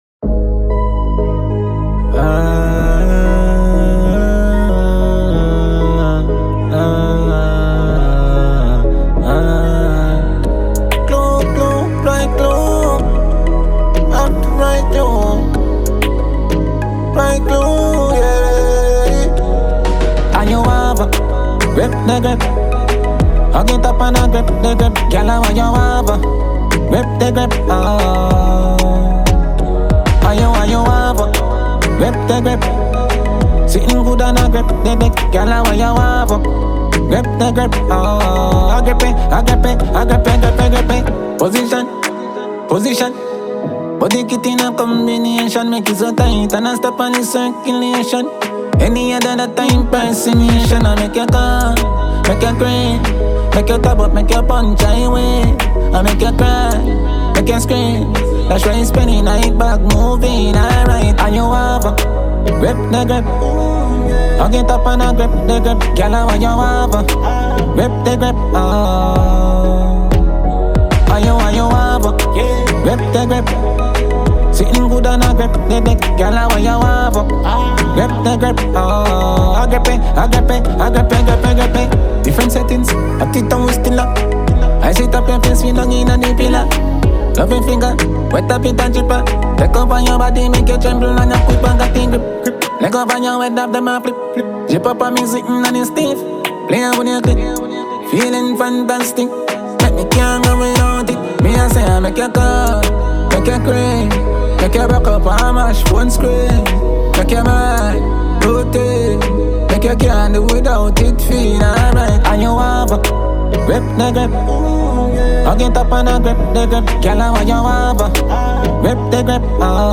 Award-winning Jamaican dancehall musician